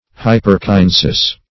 Meaning of hyperkinesis. hyperkinesis synonyms, pronunciation, spelling and more from Free Dictionary.
Search Result for " hyperkinesis" : The Collaborative International Dictionary of English v.0.48: Hyperkinesis \Hy`per*ki*ne"sis\, n. [NL., fr. Gr.